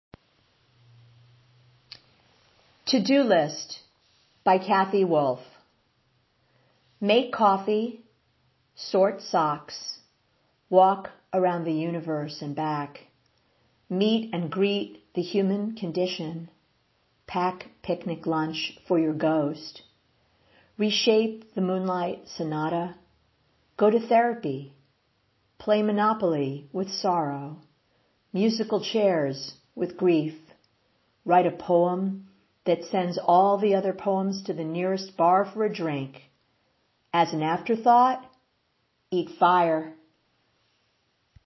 Below is a poem of Kathi’s, “To Do List,” read by yours truly.